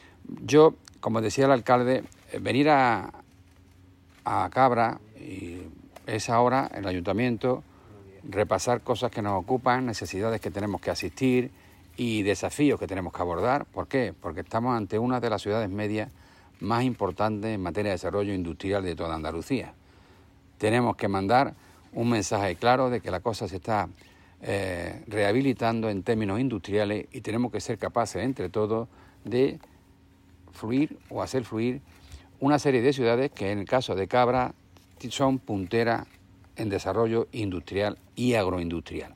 SONIDO.-Visita-Cabra.-Salvador-Fuentes-1.mp3